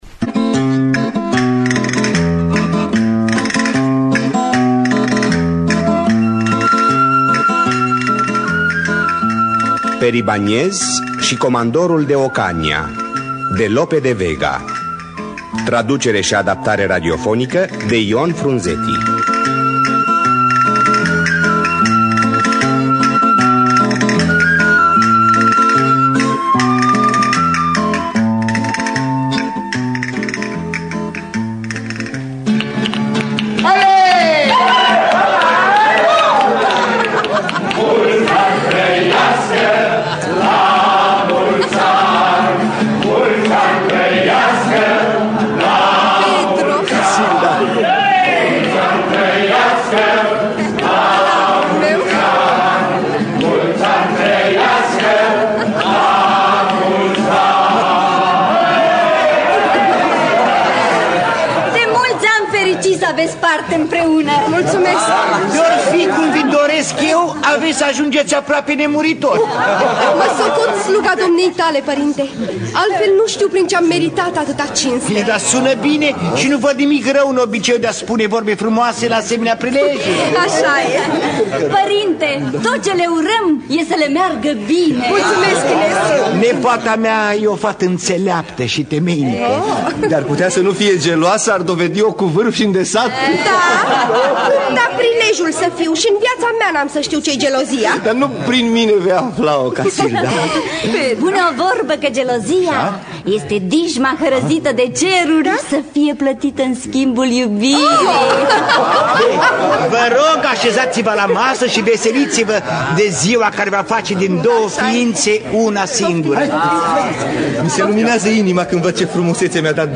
Traducerea şi adaptarea radiofonică de Ion Frunzetti.